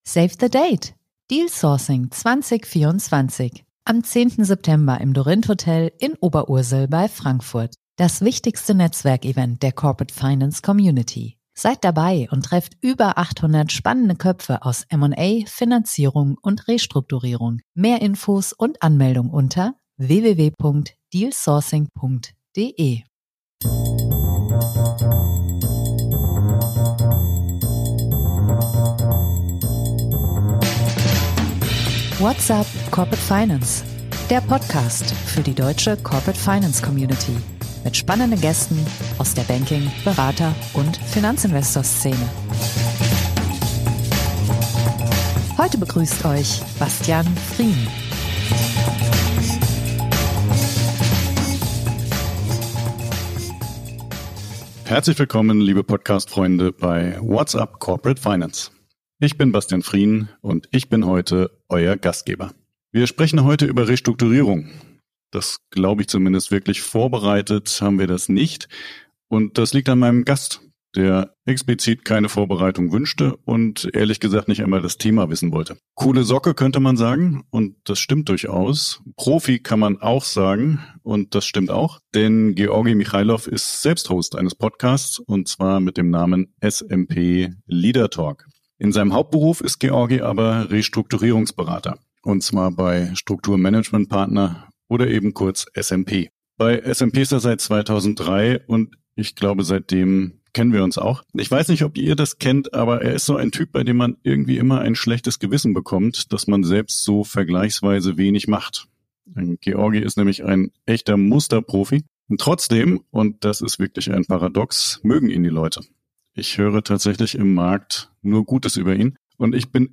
im Gespräch ~ What's up